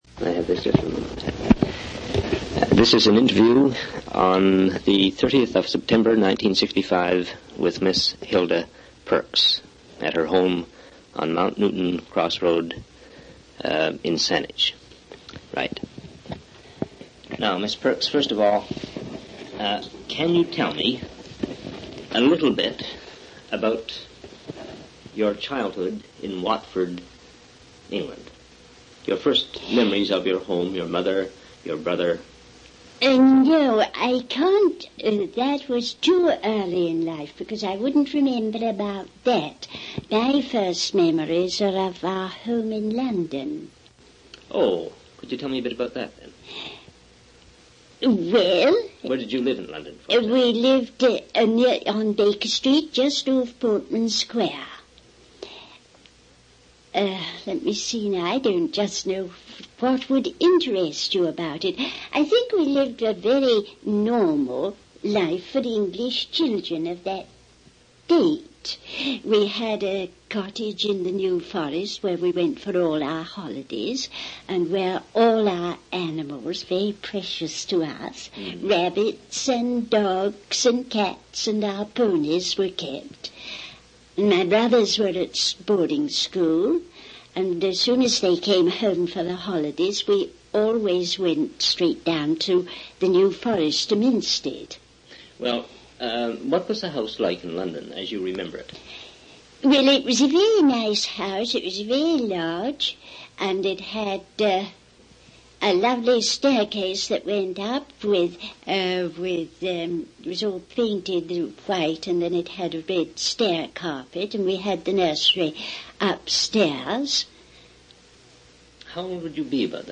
One original sound tape reel (ca. 40 min.) : 1 7/8 ips, 2 track, mono.
reminiscences sound recordings interviews oral histories (literary genre)